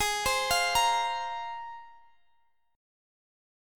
Listen to G#6add9 strummed